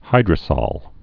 (hīdrə-sôl, -sōl, -sŏl)